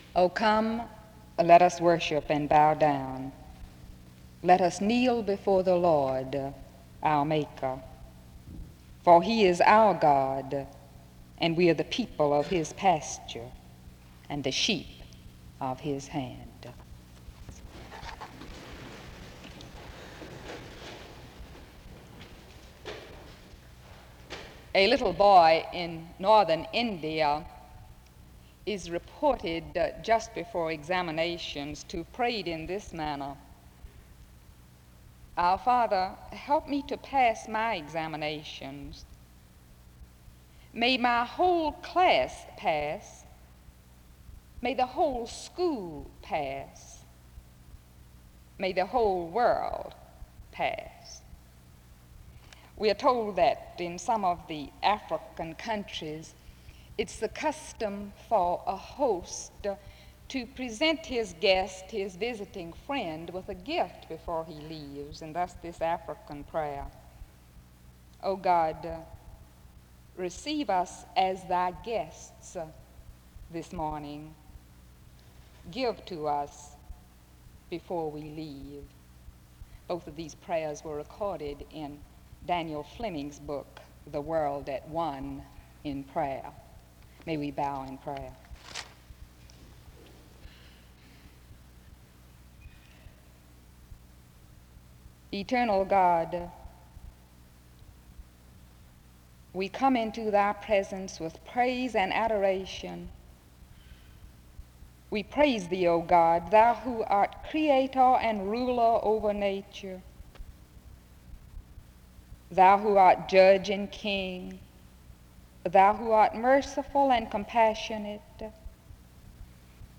The service opens with a scripture reading from 0:00-0:18. A short word on prayer is given from 0:25-1:30. A prayer is offered from 1:31-4:22. The speaker is introduced from 4:28-10:00. Music plays from 10:12-15:27.